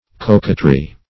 Coquetry \Co*quet"ry\, n.; pl. Coquetries. [F. coquetterie.]